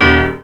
Index of /90_sSampleCDs/USB Soundscan vol.03 - Pure Electro [AKAI] 1CD/Partition E/04-PIANOS